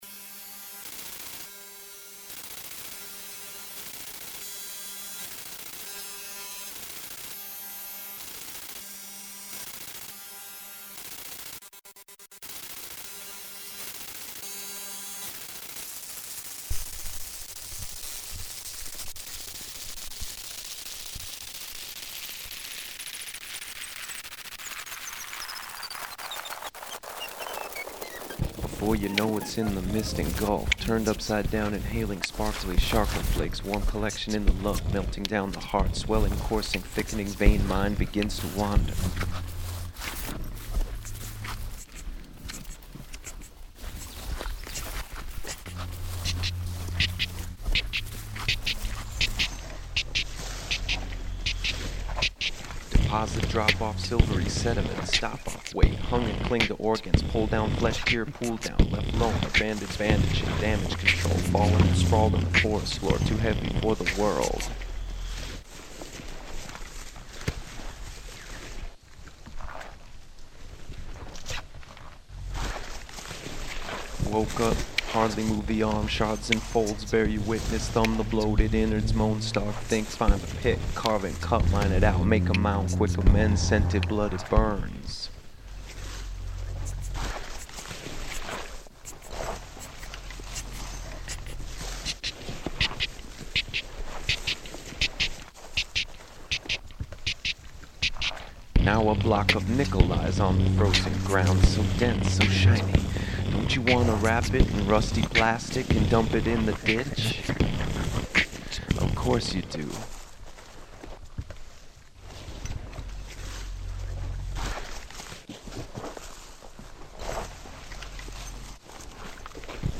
recorded in the forest  february - april 2006
additional manipulated field recordings